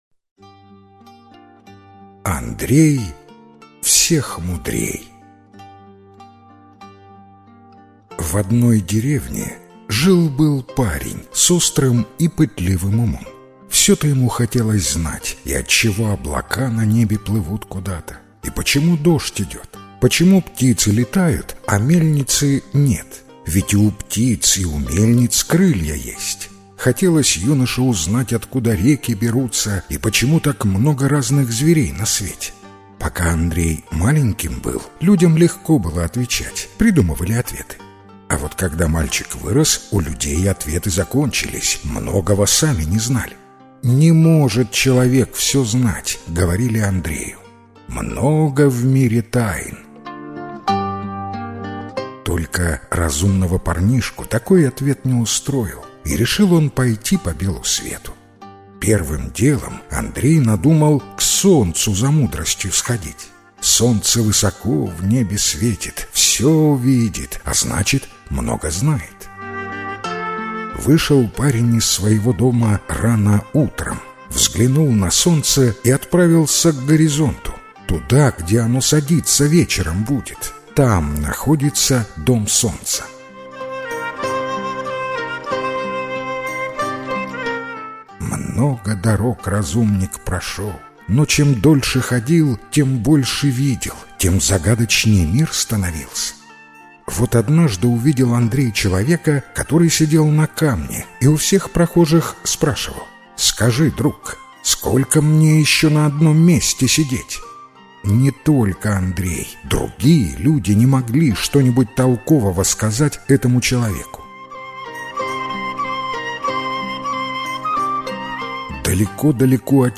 Андрей всех мудрей - белорусская аудиосказка - слушать онлайн